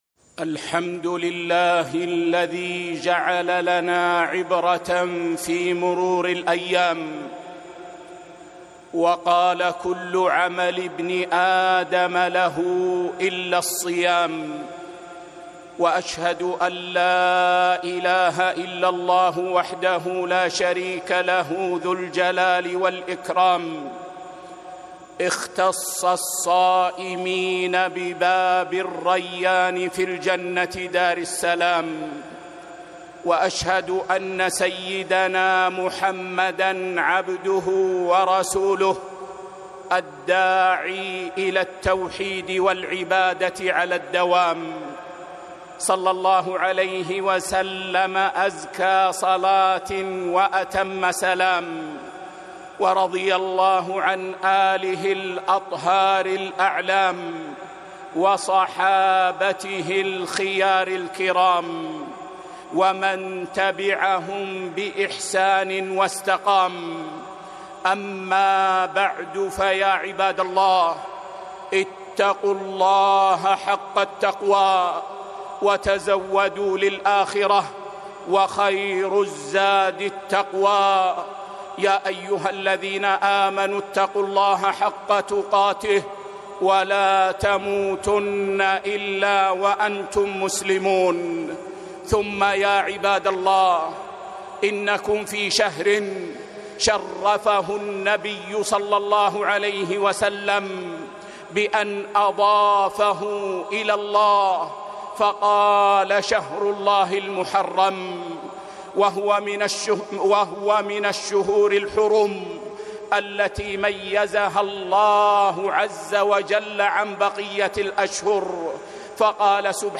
خطبة - شهر المحرم والحر